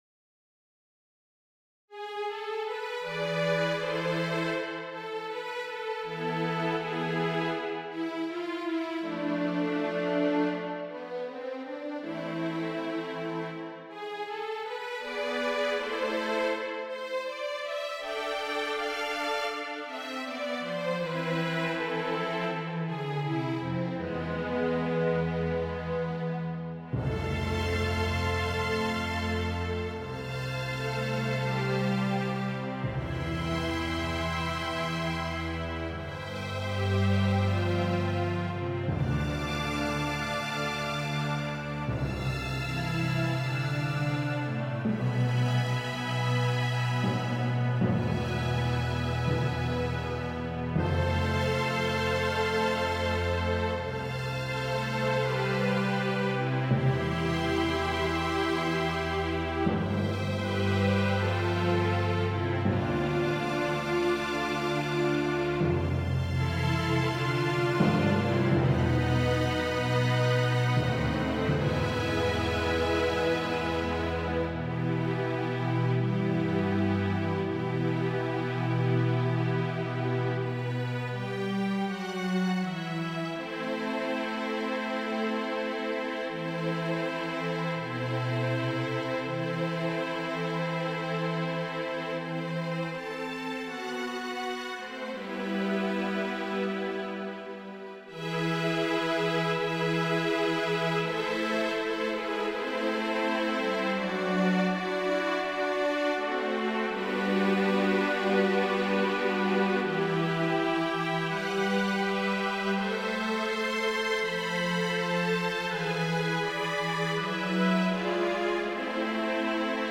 An orchestral music sketch.